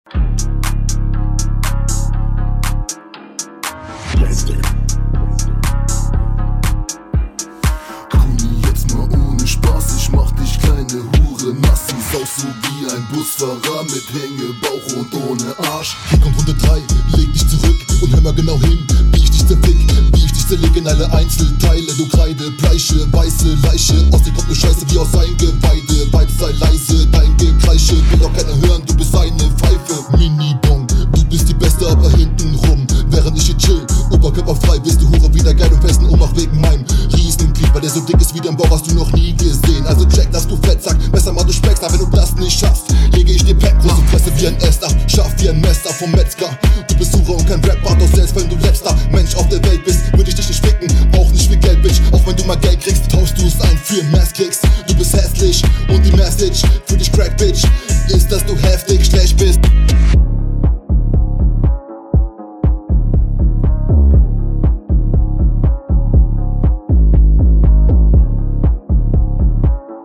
Flow:3 Text:2 Soundqualität: 1, mische gefällt mir nicht Allgemeines:ist ganz solide, die flowvariatonen sind nice
Diese Runde ist sauberer abgemischt, aber der Bass ist m. E. viel zu stark.